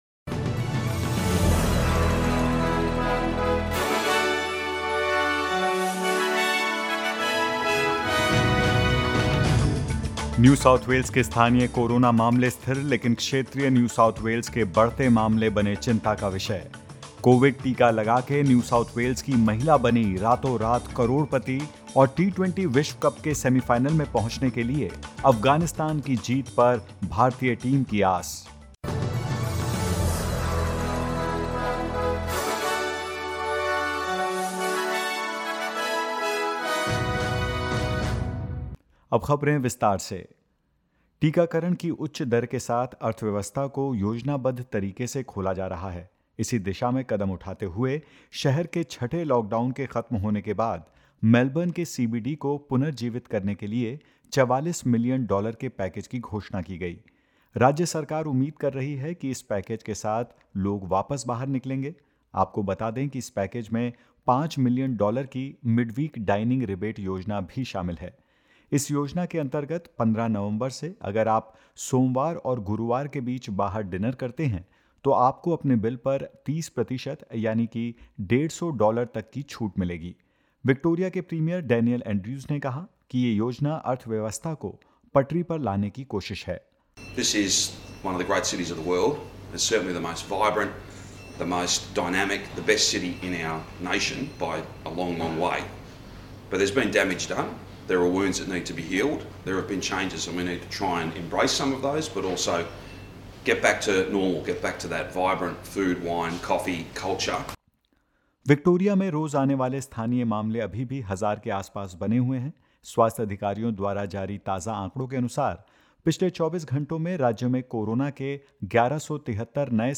In this latest SBS Hindi news bulletin of Australia and India: Package of $44 million unveiled to revitalize Melbourne's CBD; Victoria records 1,173 new COVID-19 cases and nine deaths and more.